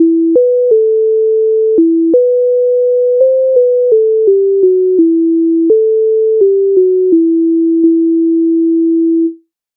MIDI файл завантажено в тональності e-moll
Зашуміла ліщинонька Українська народна пісня з обробок Леонтовича с, 127
Ukrainska_narodna_pisnia_Zashumila_lishchynonka.mp3